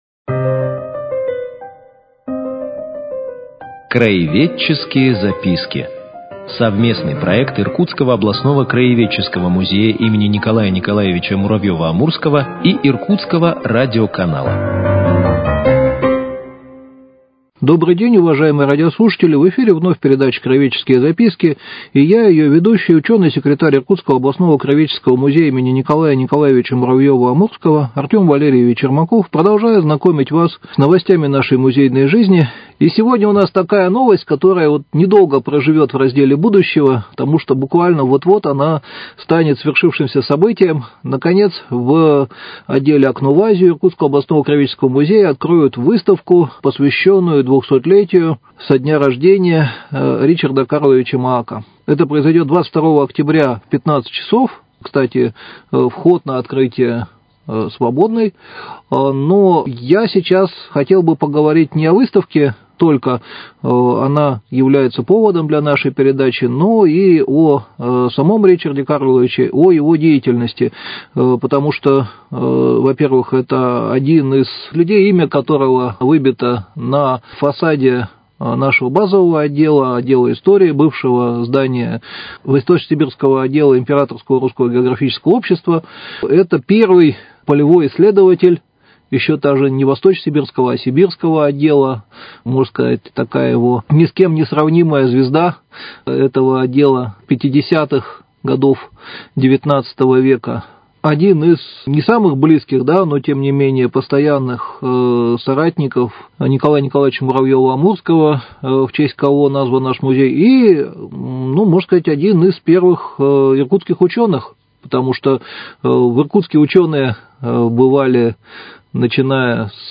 Цикл передач – совместный проект Иркутского радио и Иркутского областного краеведческого музея им. Н.Н.Муравьёва - Амурского.